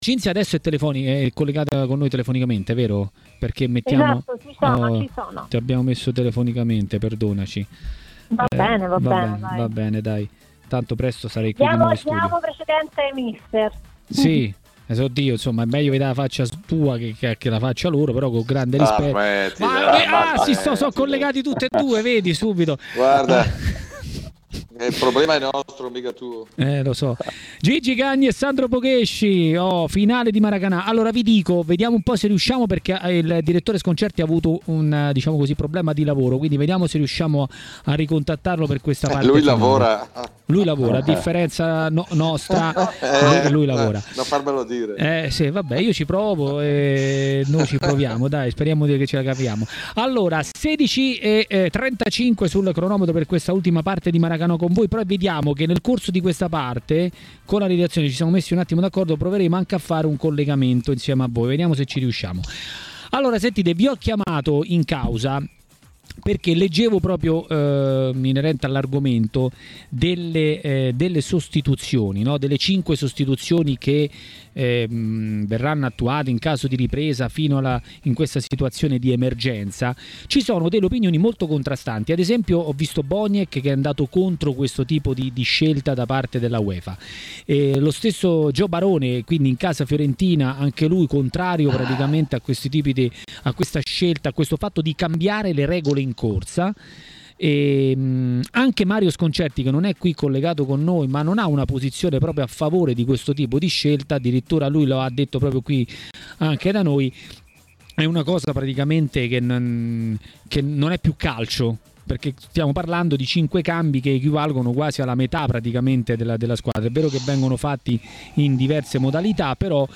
A TMW Radio, durante Maracanà, è il momento di mister Luigi Cagni.